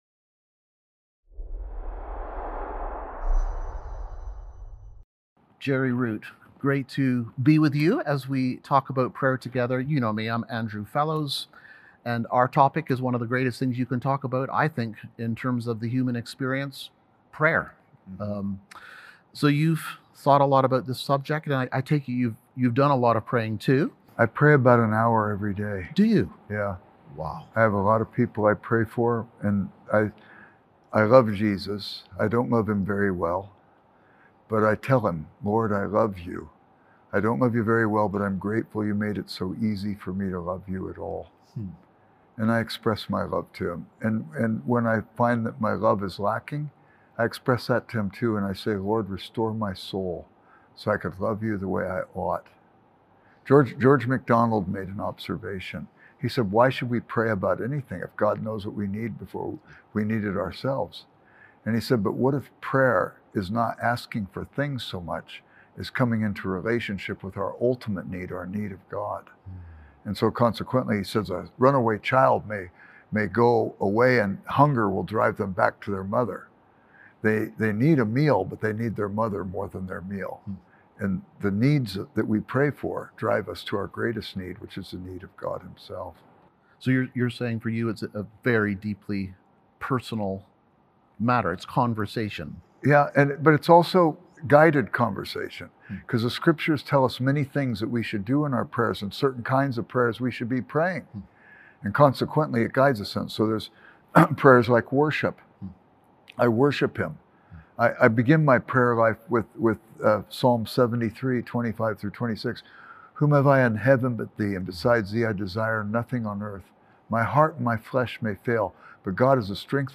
Leader-to-Leader Interview—Prayer: A Dialogue with God